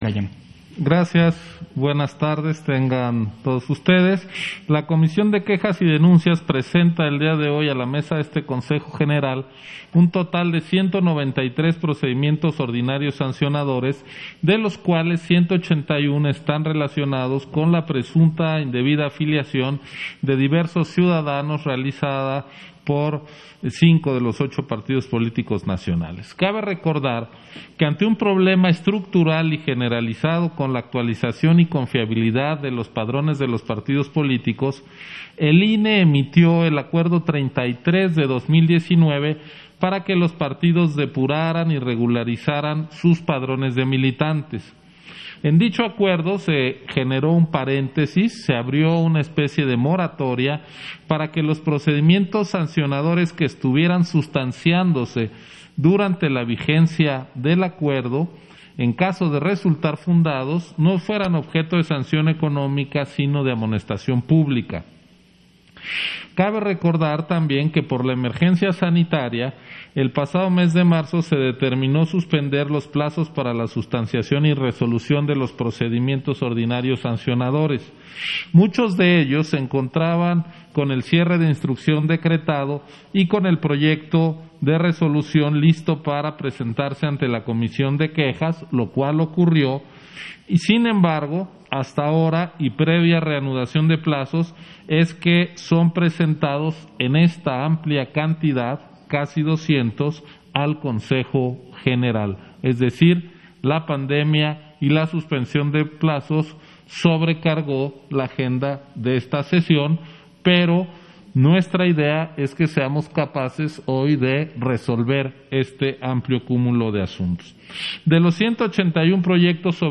071020_AUDIO_INTERVENCIÓN-CONSEJERO-CIRO-MURAYAMA-PUNTO-1-SESIÓN-EXT. - Central Electoral